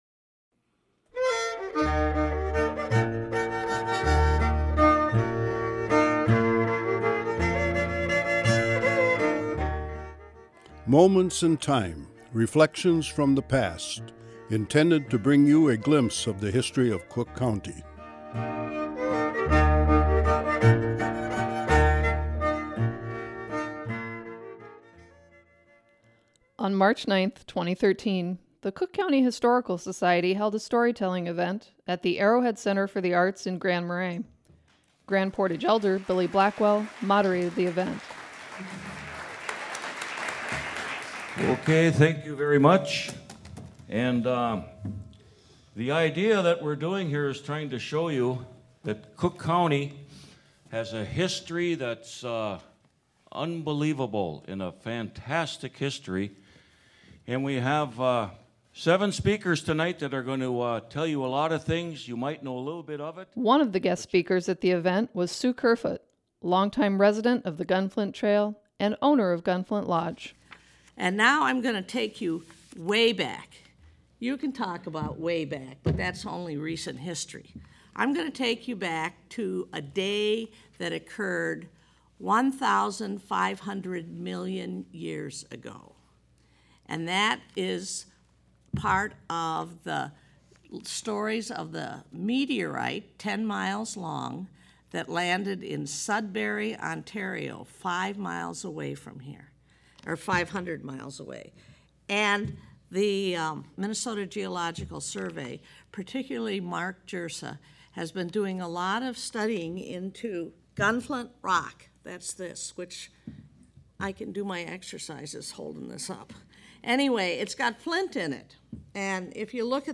On March 9, 2013, the Cook County Historical Society held a storytelling event at the Arrowhead Center for the Arts in Grand Marais.